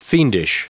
Prononciation du mot fiendish en anglais (fichier audio)
Prononciation du mot : fiendish